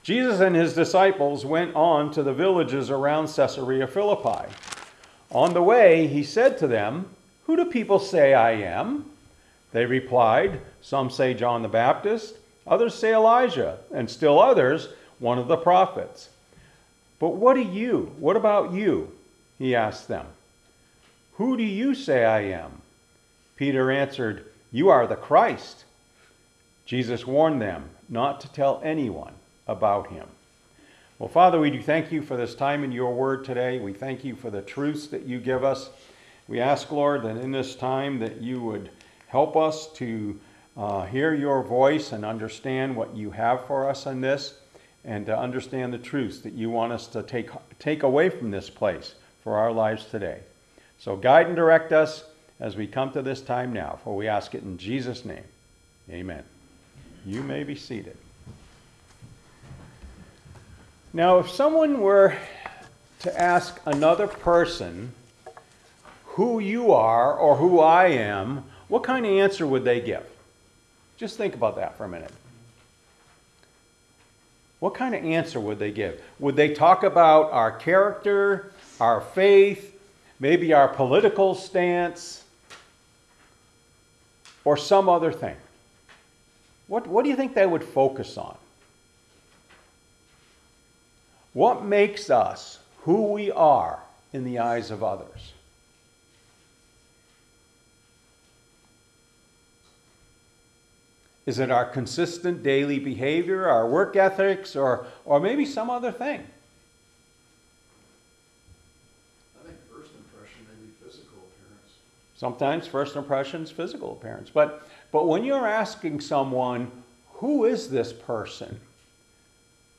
Then open from download page Sermons Comments Off on Mark 8:27-30 Who do They say You Are audio